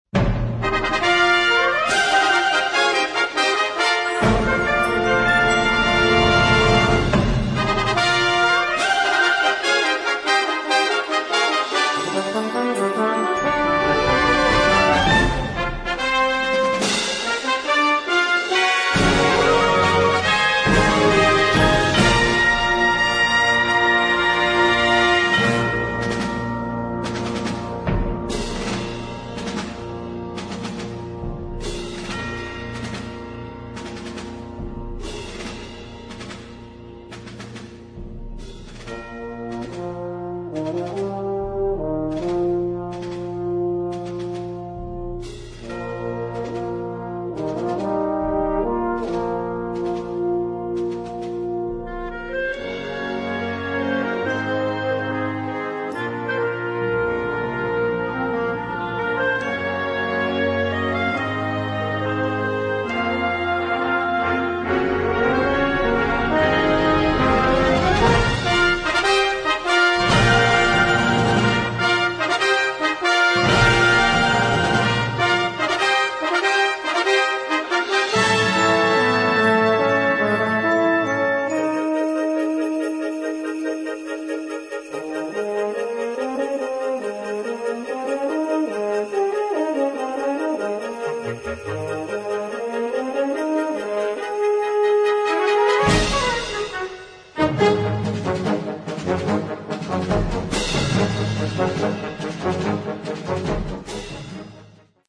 Noten für Blasorchester.